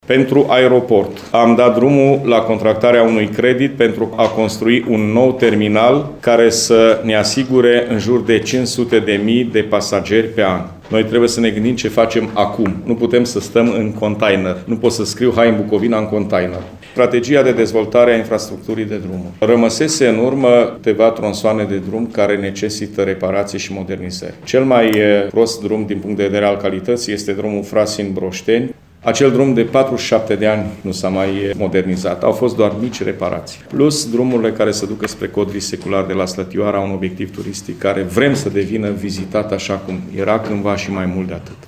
El a detaliat lucrările care vor fi executate.